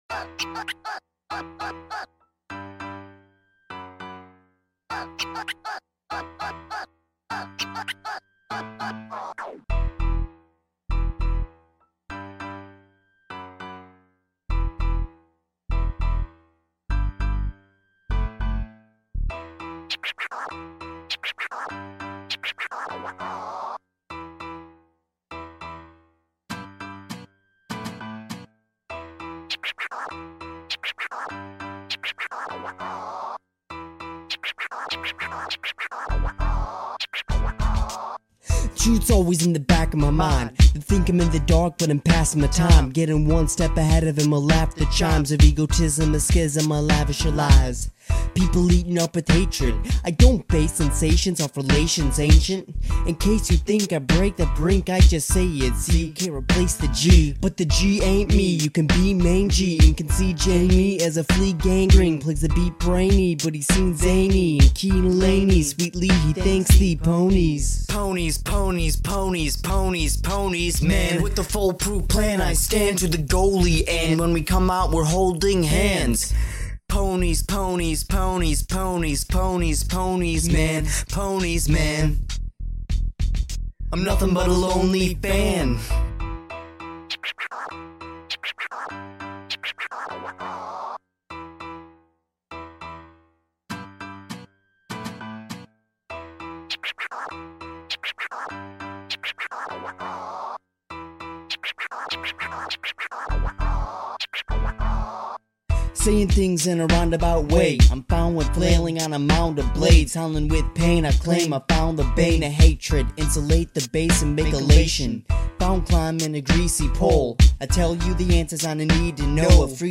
Another Rap!